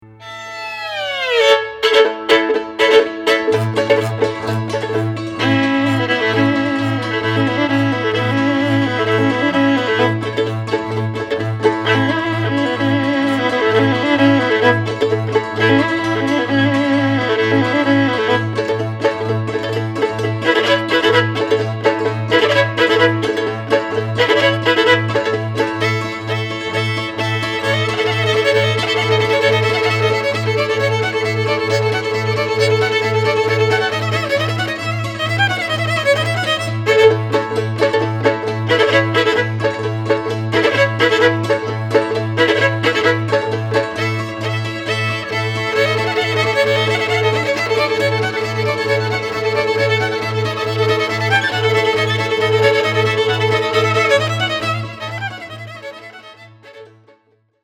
Recorded at Bay Records, Berkeley, CA, May 2003
Genre: Klezmer.
violin, tsimbl, bass   1:48